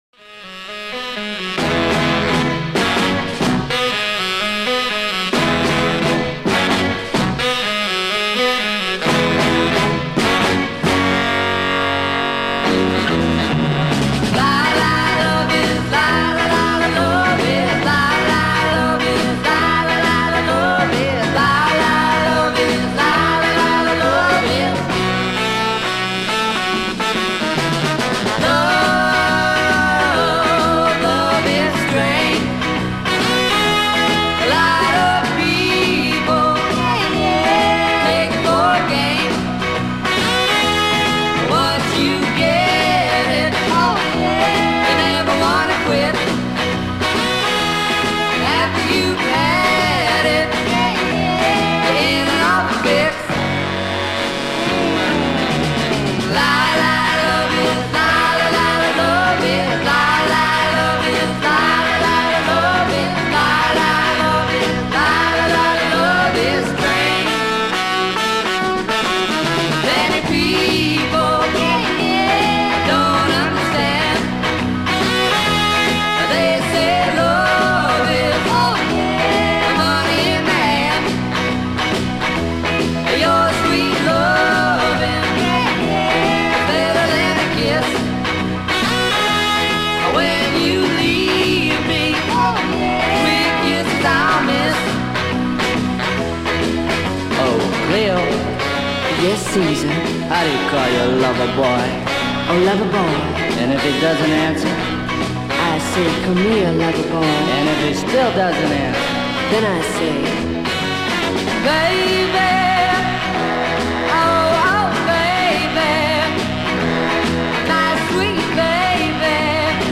ska version